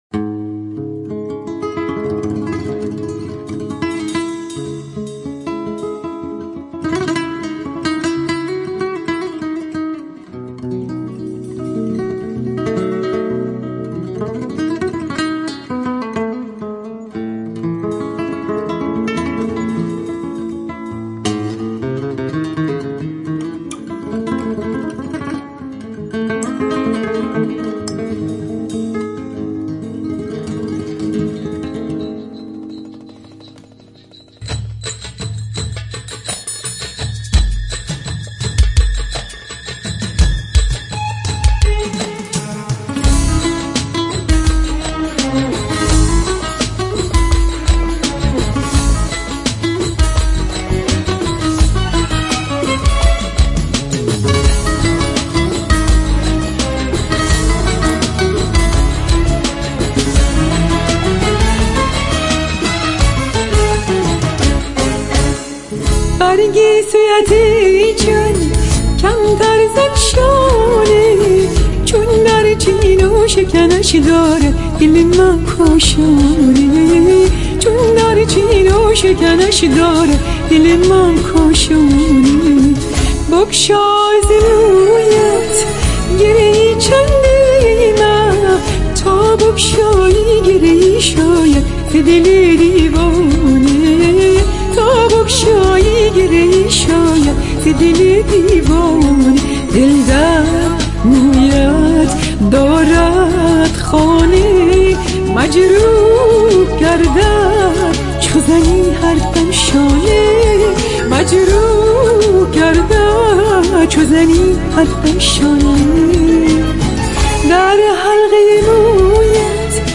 با صدای زن 1